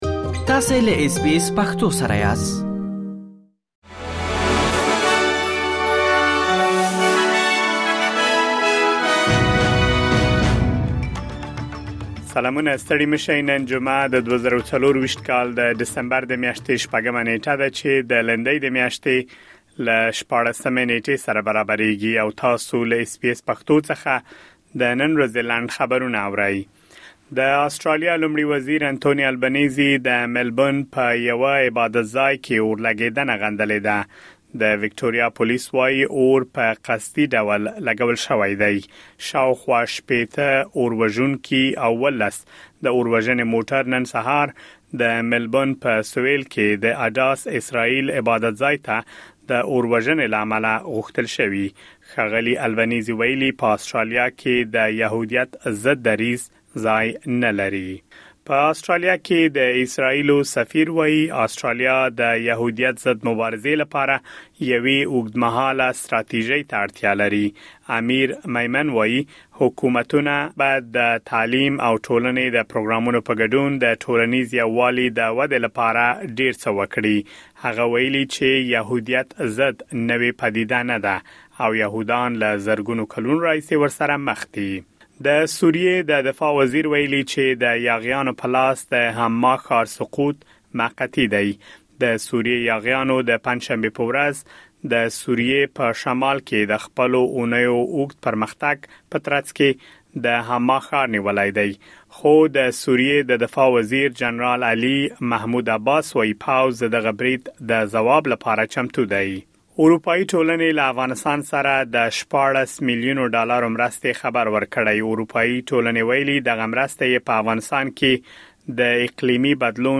د اس بي اس پښتو د نن ورځې لنډ خبرونه |۶ ډسمبر ۲۰۲۴
د اس بي اس پښتو د نن ورځې لنډ خبرونه دلته واورئ.